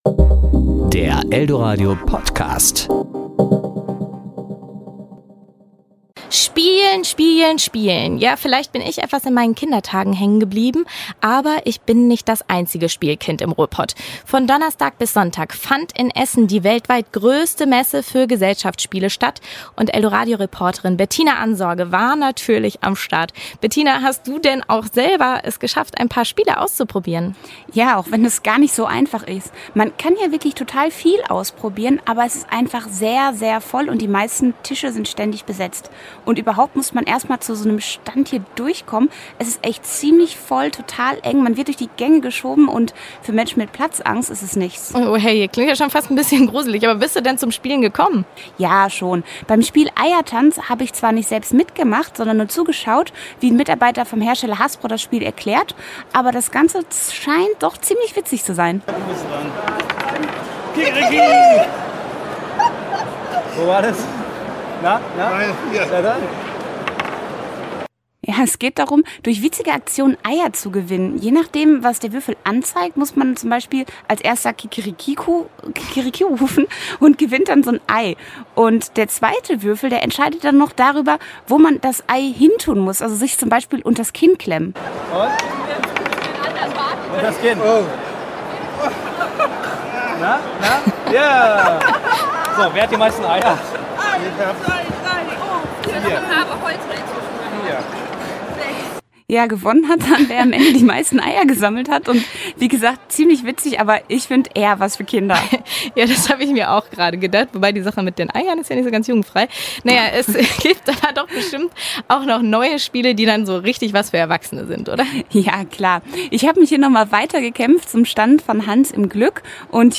Von Donnerstag bis Sonntag fand in Essen die weltweit größte Messe für Gesellschaftsspiele statt.
Serie: Beiträge